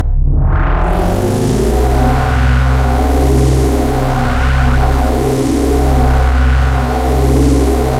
engine_loop.wav